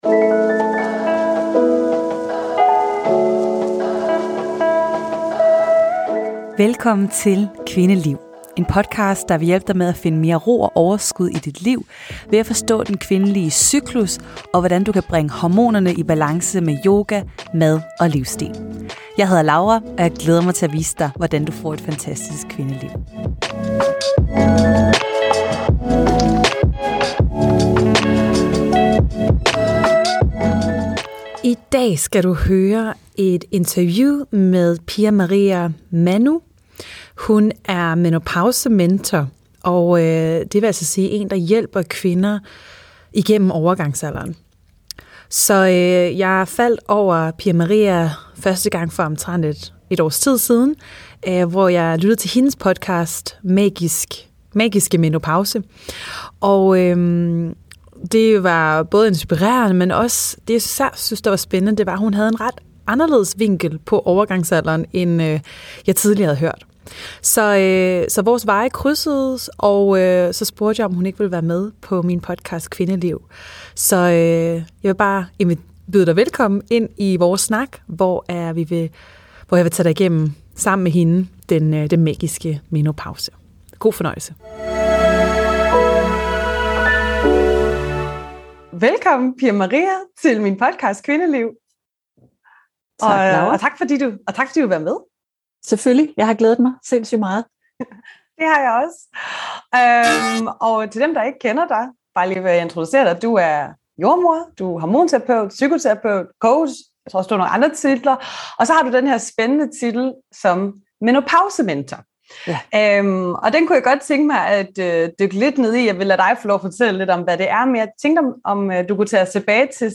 Podcasten blev optaget over en Zoom-forbindelse, derfor er lydkvaliteten lidt lavere end den plejer.